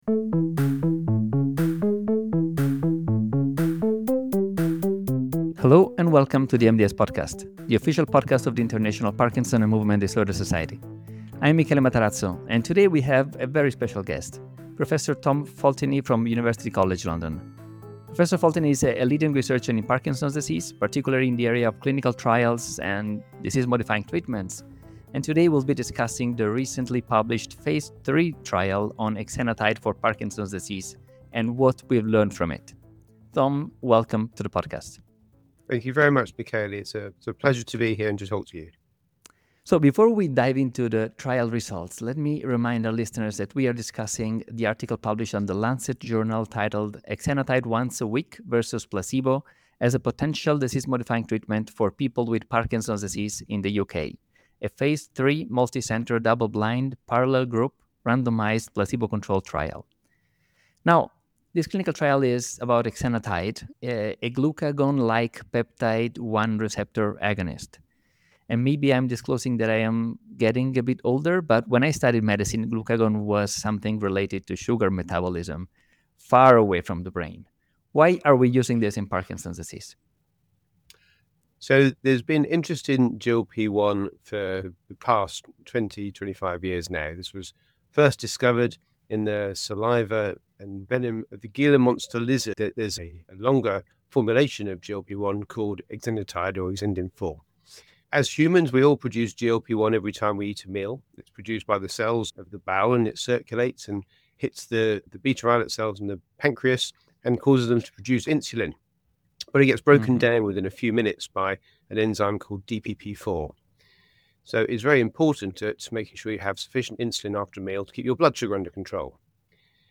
Each episode of the International Parkinson and Movement Disorder Society podcast discusses a relevant development in the field, including highlighted journal articles and interviews with the authors.